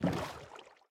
Minecraft Version Minecraft Version 1.21.5 Latest Release | Latest Snapshot 1.21.5 / assets / minecraft / sounds / entity / boat / paddle_water8.ogg Compare With Compare With Latest Release | Latest Snapshot
paddle_water8.ogg